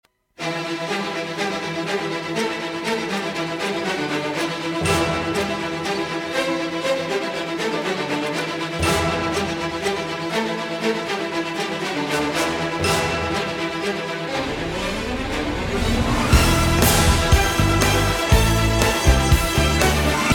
симфо версия